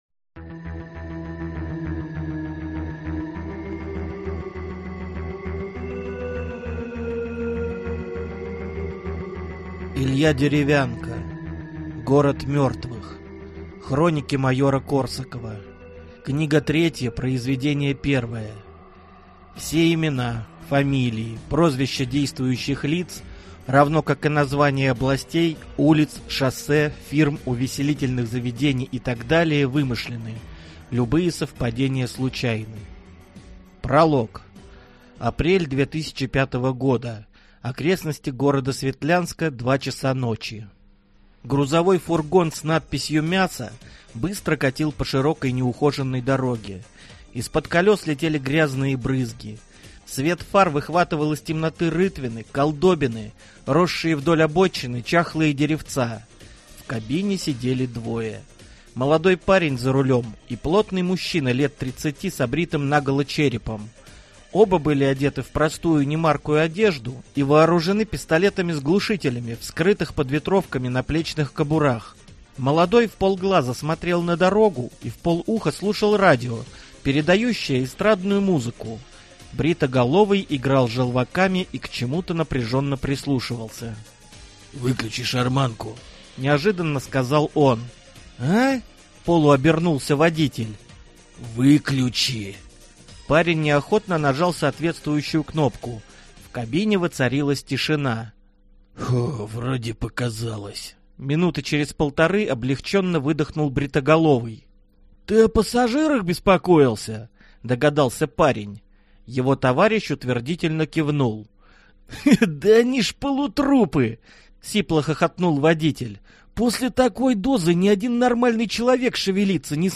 Aудиокнига Город мертвых